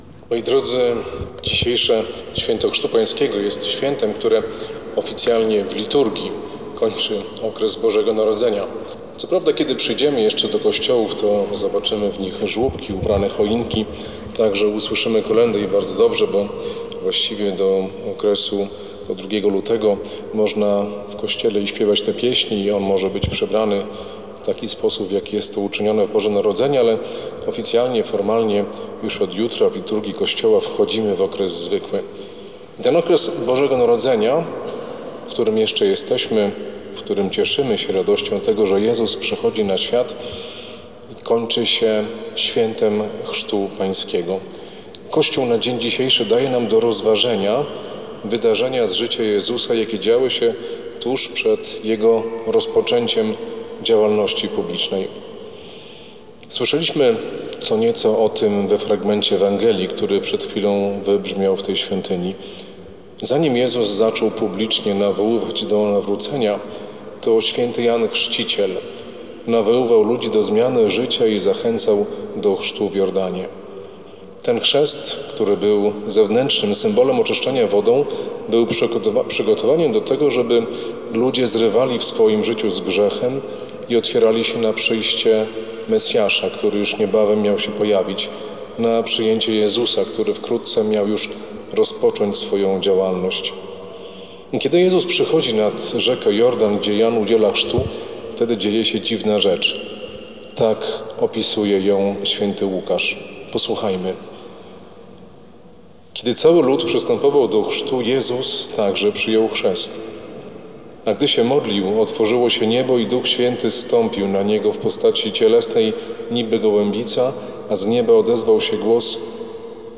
W Niedzielę Chrztu Pańskiego biskup pomocniczy diecezji warszawsko-praskiej bp Tomasz Sztajerwald odprawił Mszę świętą w konkatedrze Matki Bożej Zwycięskiej na Kamionku. W homilii duchowny wyjaśniał znaczenie chrztu Jezusa w Jordanie oraz istotę chrztu każdego chrześcijanina.